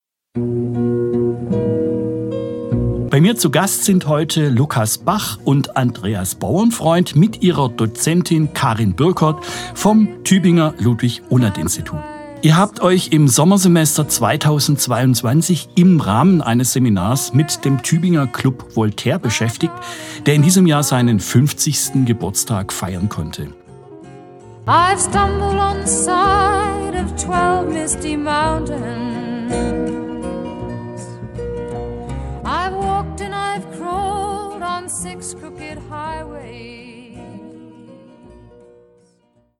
Radio Micro-Europa, der Tübinger Campusfunk: Sendung (444) "50 Jahre Club Voltaire", Studiogespräch mit Gästen aus dem LUI am Sonntag, den 30. Oktober 2022, 12-13 Uhr im Freien Radio Wüste Welle 96,6 – Kabel: 97,45 Mhz, auch in der Mediathek.